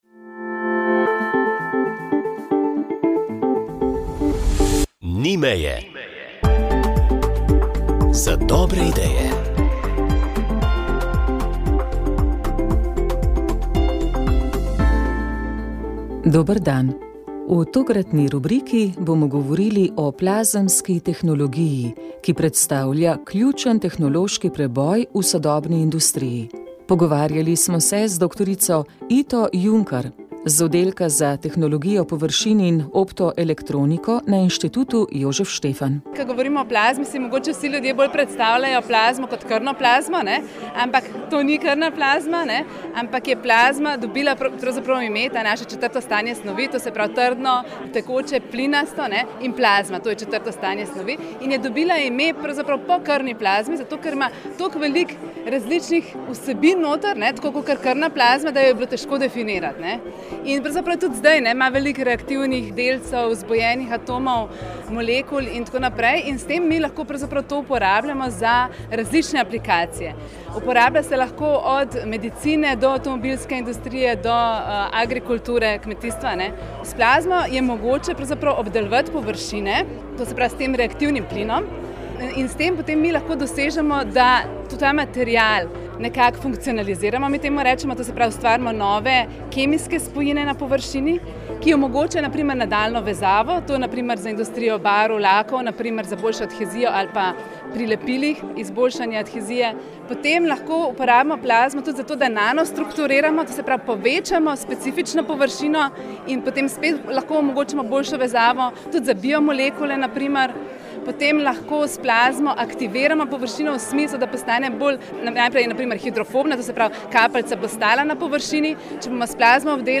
Vremenska napoved 19. december 2024